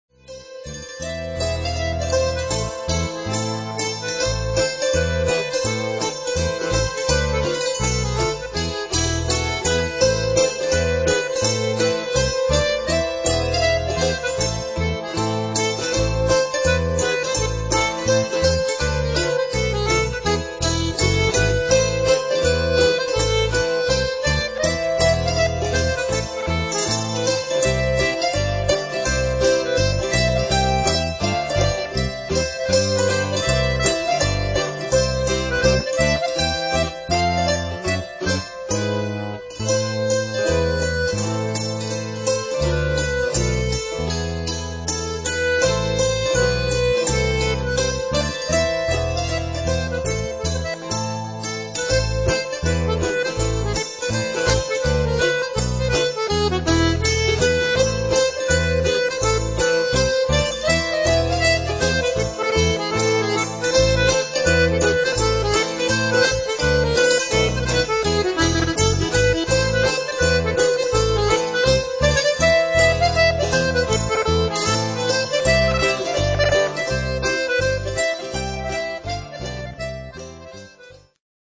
... a jaunty melody well suited to both squeezebox and dulcimer.